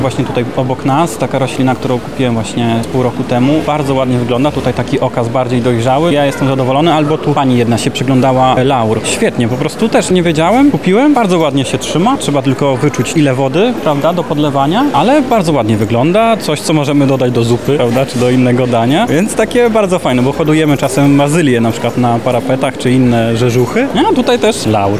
W miniony weekend (28 i 29 marca) Targi Lublin gościły Festiwal Roślin, czyli największą imprezę dla miłośników zieleni w Polsce.